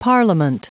Prononciation du mot parliament en anglais (fichier audio)
Prononciation du mot : parliament
parliament.wav